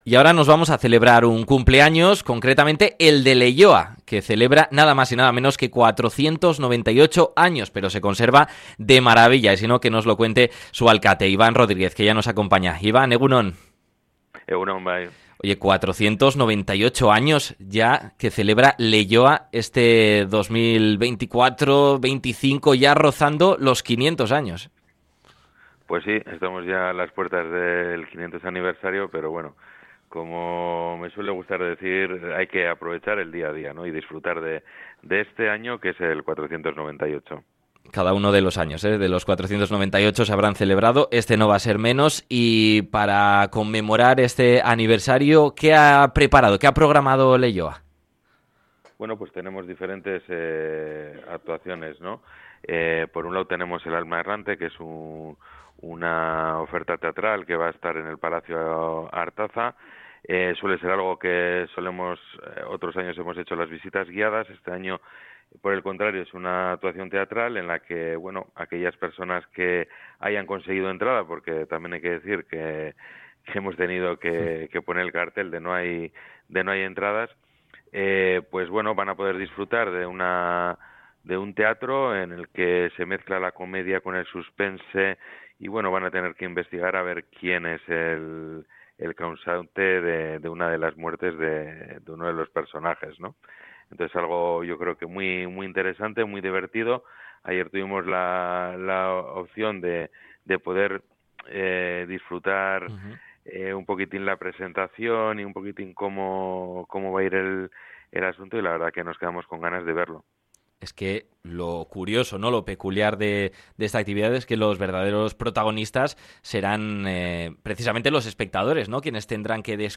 El alcalde Iban Rodríguez nos amplía los espectáculos que han programado por este aniversario desde este fin de semana